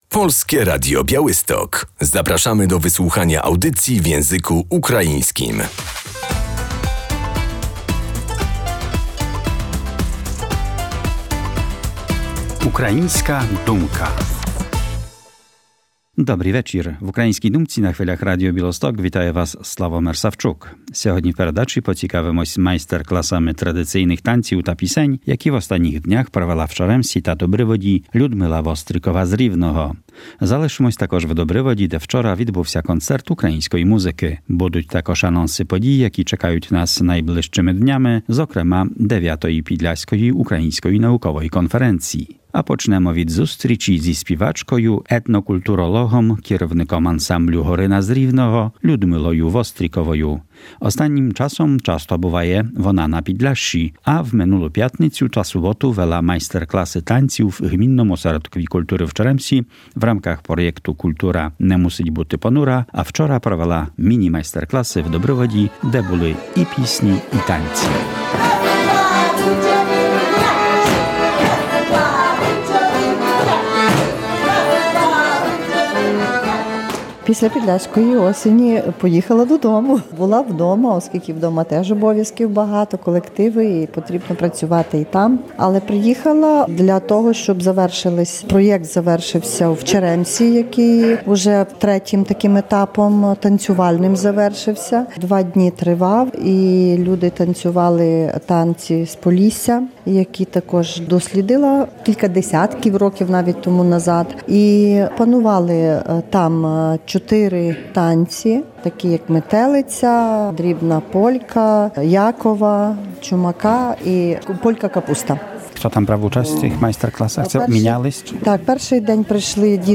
W Dobrowodzie odbył się Koncert Muzyki Ukraińskiej, zorganizowany przez Związek Ukraińców Podlasia i miejscowe Koło Gospodyń Wiejskich
Śpiewy i tańce w Dobrowodzie 10.11.2025